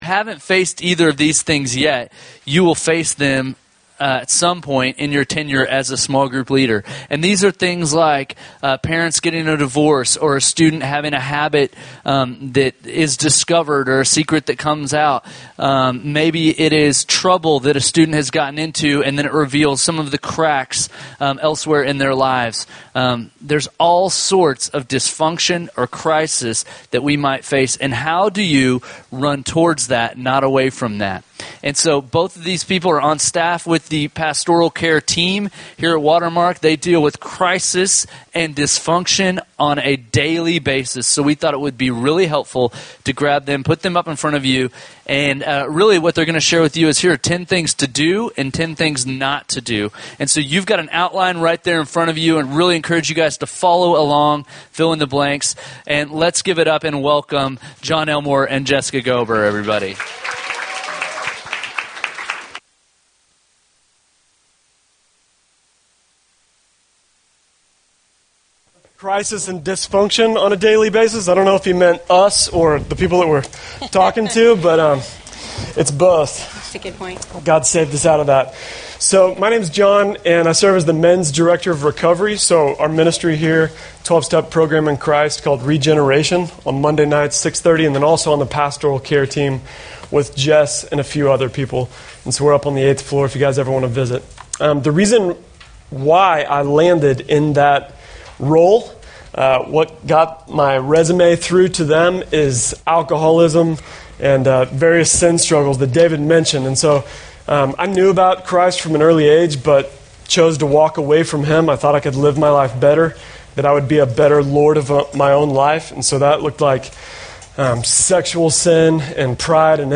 How to Pastorally Respond to Crisis or Dysfunction Audio from Student Ministry Leader Training